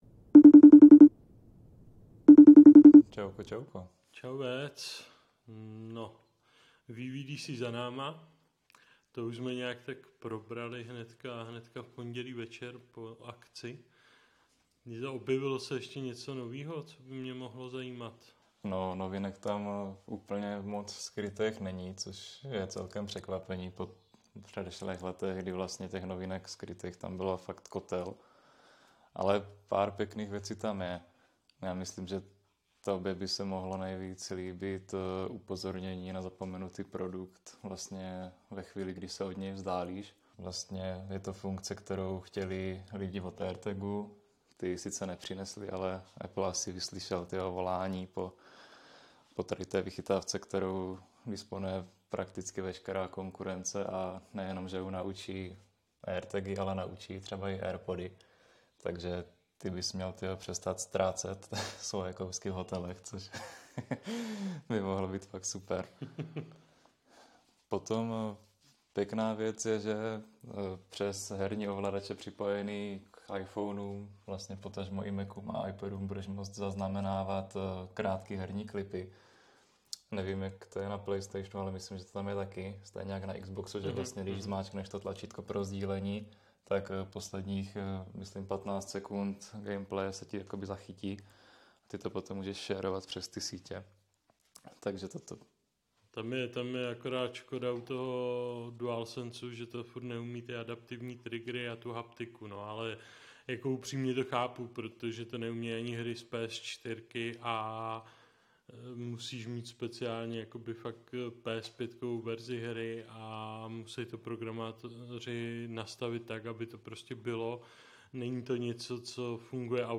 Páteční pokec přes FaceTime s LsA vol. 4